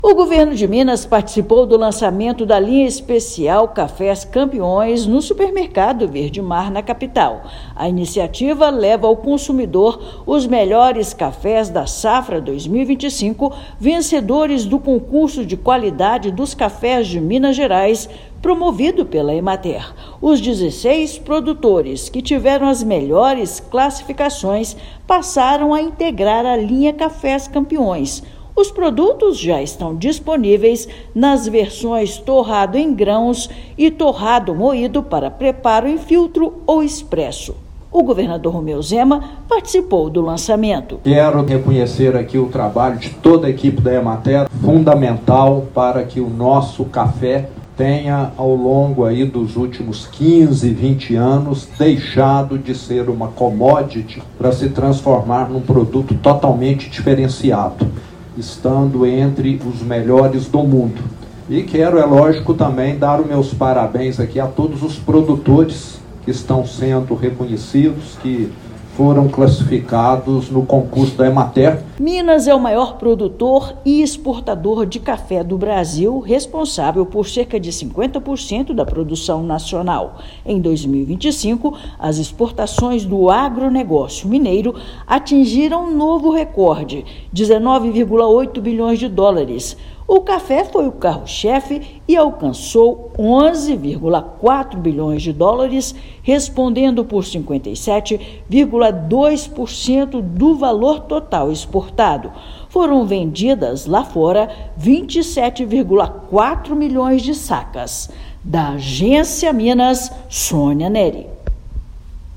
Iniciativa reúne 16 produtores mineiros e leva cafés premiados ao consumidor de Belo Horizonte. Ouça matéria de rádio.